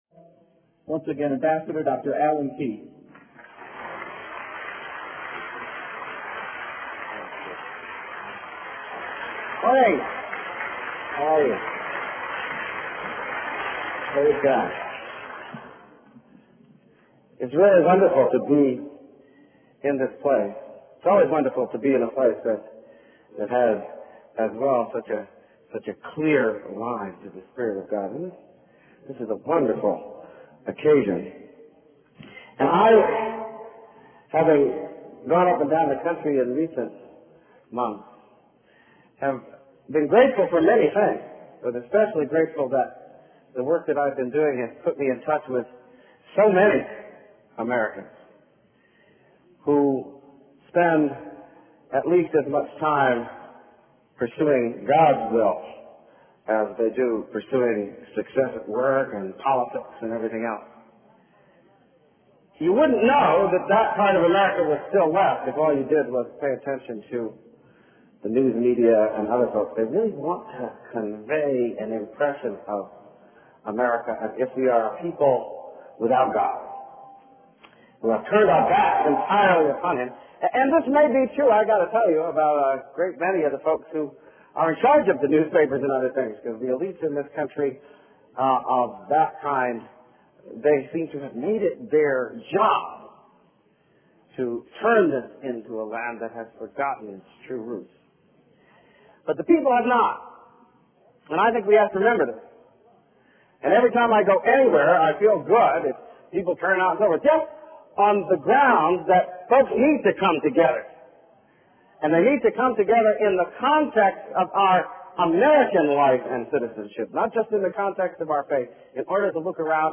MP3 audio Speech Christian prayer service in Michigan Alan Keyes July 9, 1996 It's really wonderful to be in this place.